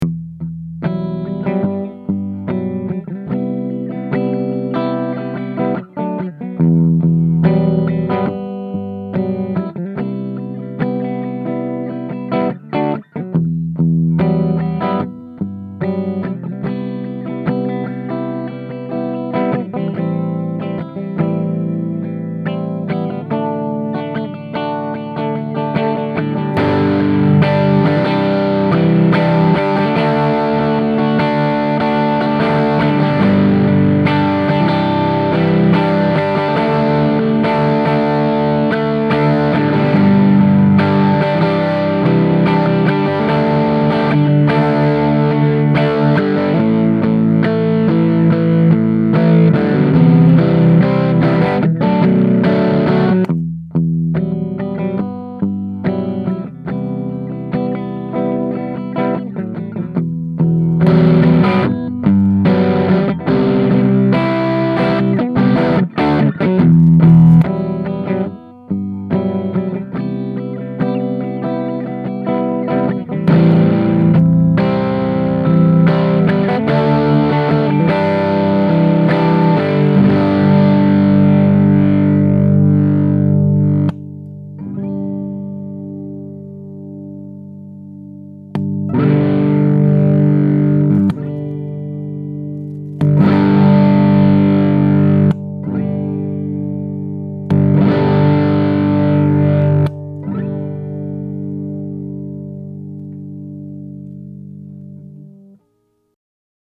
For instance, I can go from clean to full-on overdrive with the click of the foot switch; much like engaging an overdrive pedal.
I used my Aracom VRX22 for that, which is another Plexi clone but with 6V6 tubes. This amp is notable for its haunting clean tone, and monster overdrive, which comes from 1950’s NOS 6V6’s that I have biased a little hot.